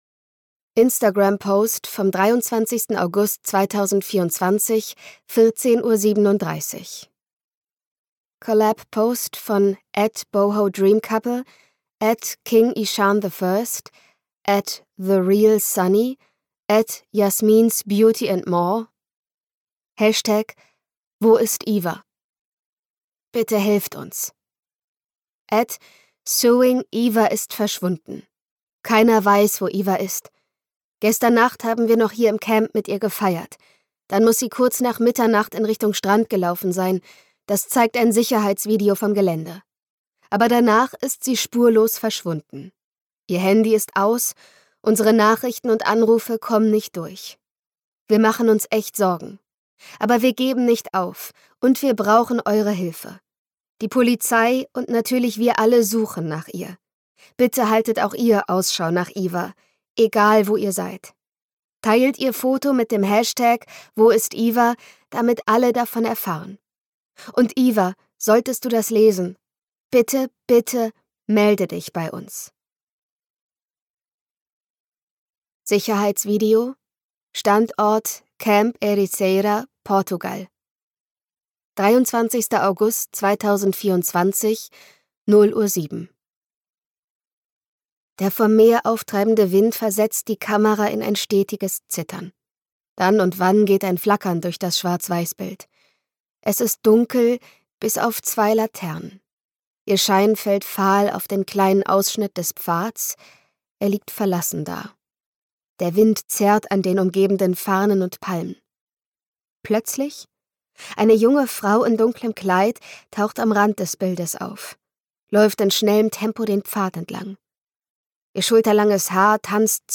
Ein absolut fesselndes New-Adult-Suspense-Hörbuch um die junge Influencerin Iva, die an der wellenumtosten Küste Portugals in ein Netz aus Liebe, Lügen und gefährlichen Machenschaften gerät …
Gekürzt Autorisierte, d.h. von Autor:innen und / oder Verlagen freigegebene, bearbeitete Fassung.